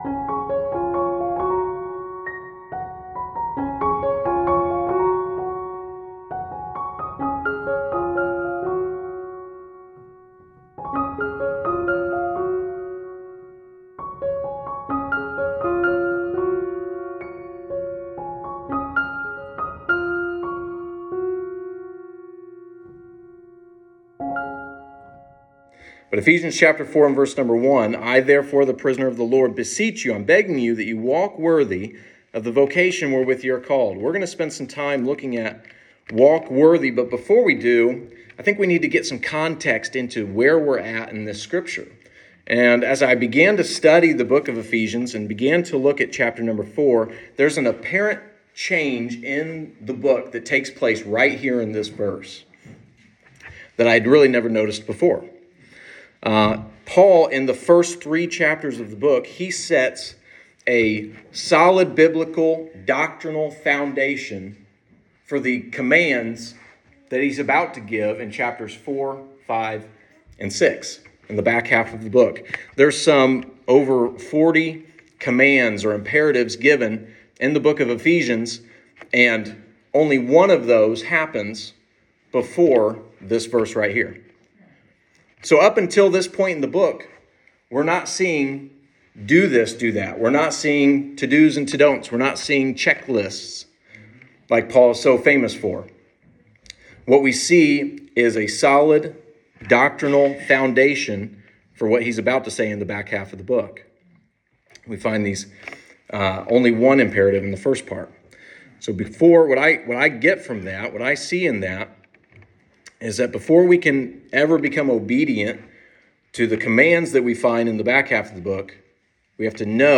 Sunday Morning – April 23, 2023